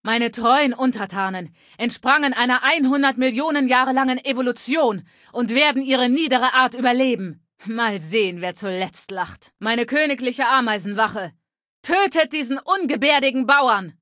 Datei:Femaleadult01default ms02 ms02superheroexplain1 0003c8d4.ogg
Fallout 3: Audiodialoge